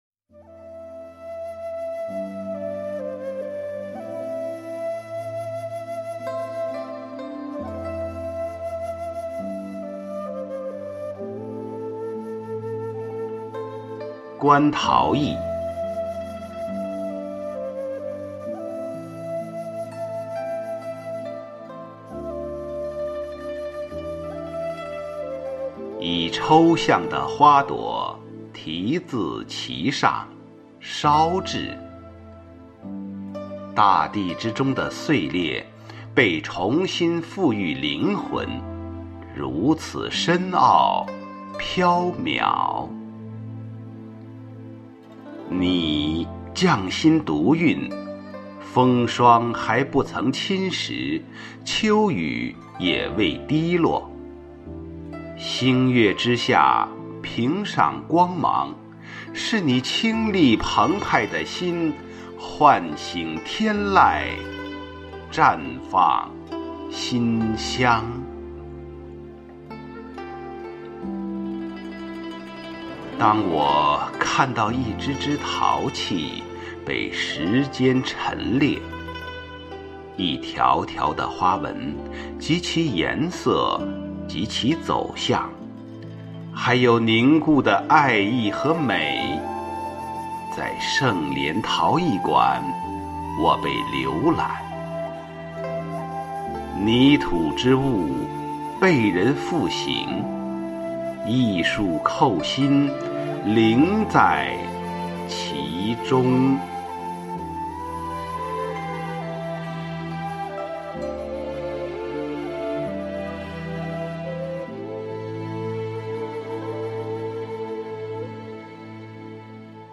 誦讀